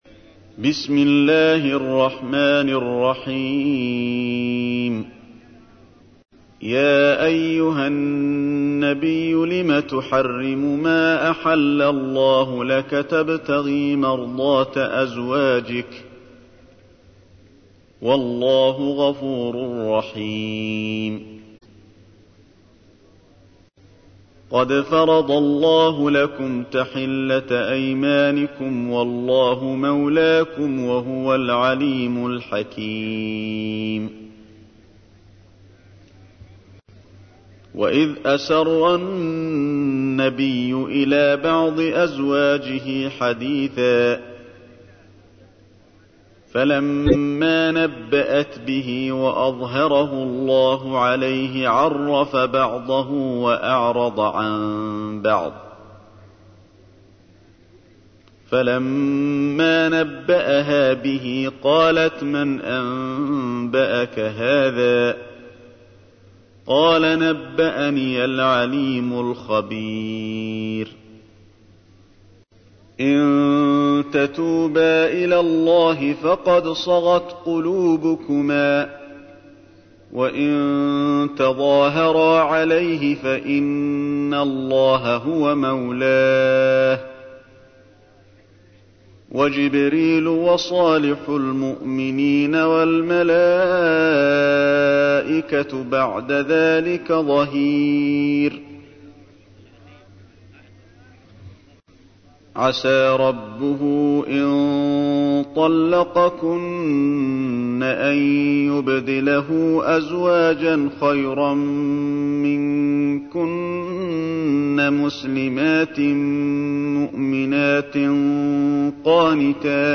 تحميل : 66. سورة التحريم / القارئ علي الحذيفي / القرآن الكريم / موقع يا حسين